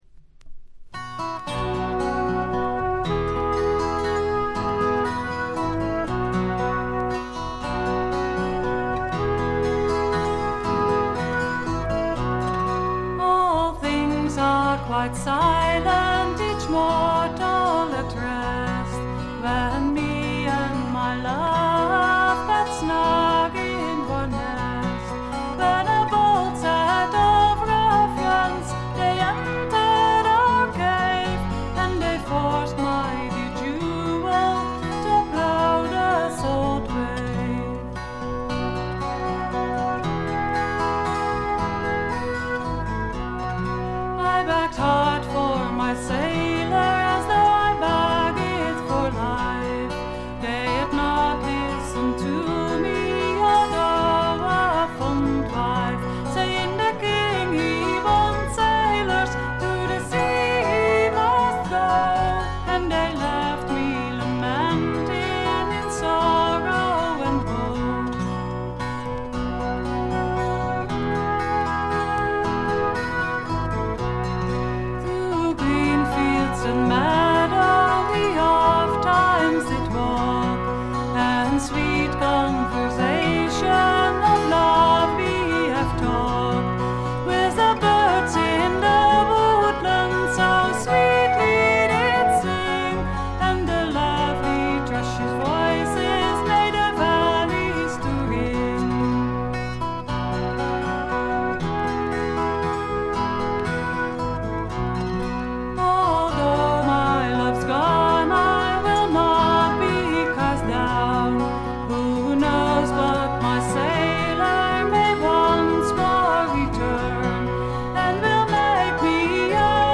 1stの木漏れ日フォーク的な穏やかさ、暖かさを残しながらも、メリハリの利いた演奏と構成で完成度がぐんと上がっています。
ジェントルな男性ヴォーカル、可憐で美しい女性ヴォーカル（ソロパートが少ないのがちょっと不満）、見事なコーラスワーク。
Acoustic Guitar, Vocals
Accordion
Bass Guitar
Flute
Oboe
Violin